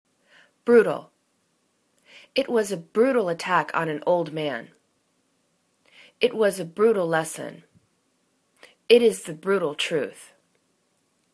bru.tal     /'bru:tl/    adj